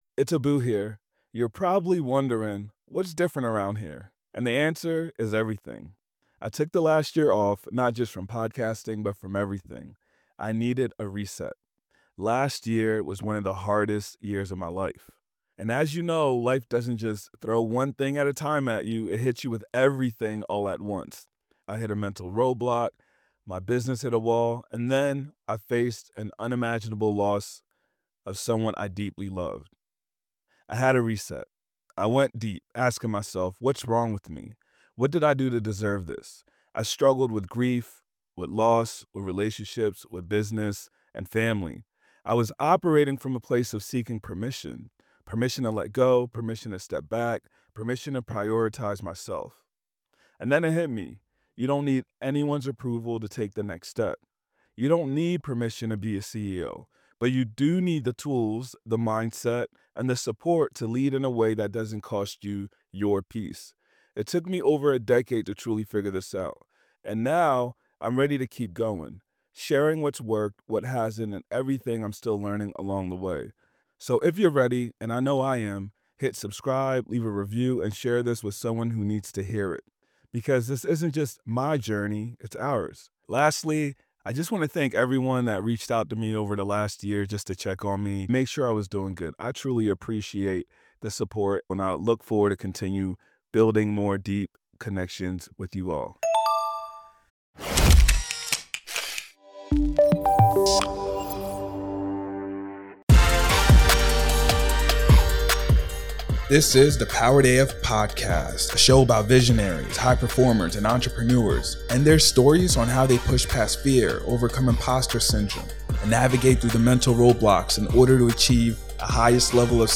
This is a really great interview especially if you're trying to figure out how you should spend your time and money when it comes to marketing... let's begin this week's podcast!